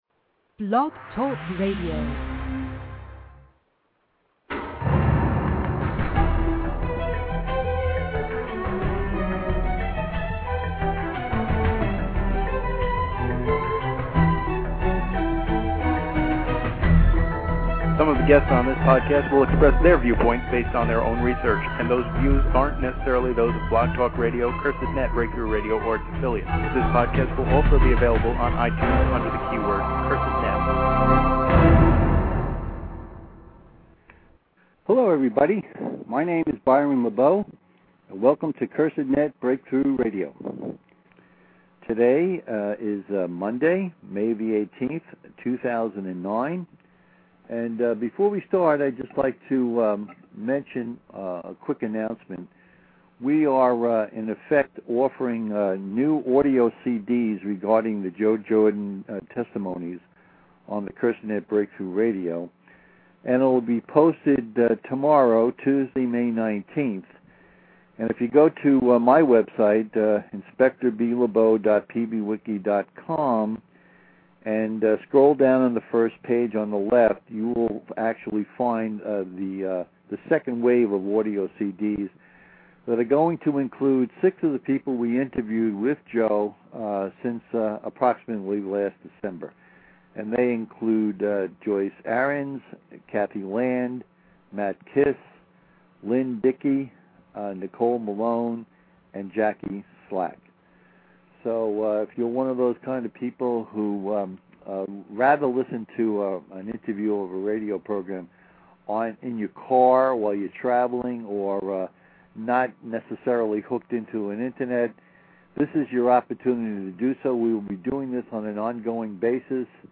The CE4 Research Group Testimony – 56